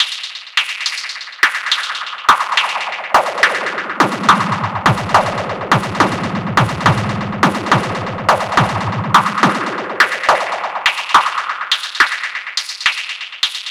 FX 140-BPM 2.wav